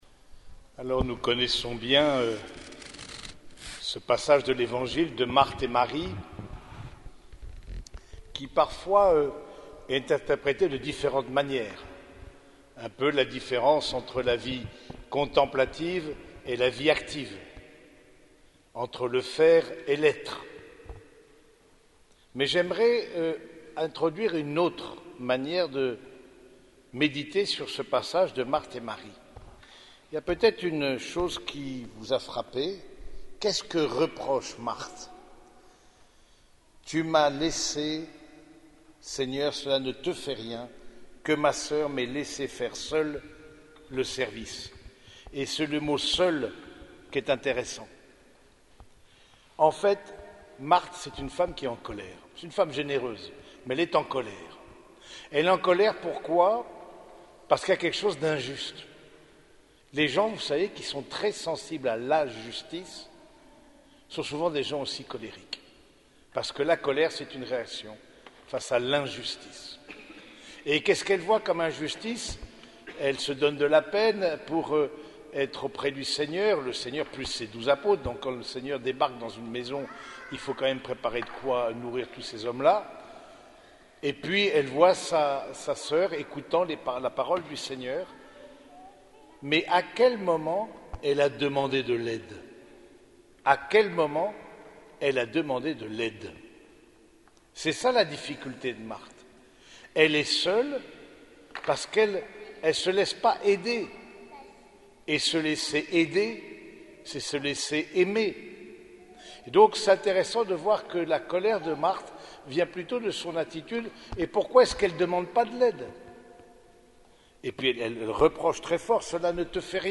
Homélie du 16e dimanche du Temps Ordinaire
Cette homélie a été prononcée au cours de la messe dominicale à l’église Saint-Germain de Compiègne.